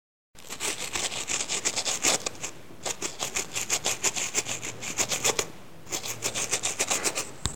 Rayando pan duro
Grabación sonora que capta el sonido que se produce al rayar un pedazo de pan duro contra un rayador (fabricación de pan rayado).
Sonidos: Acciones humanas
Sonidos: Hogar